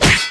拳头击中金属盔甲2zth070523.wav
通用动作/01人物/03武术动作类/拳头击中金属盔甲2zth070523.wav
• 声道 單聲道 (1ch)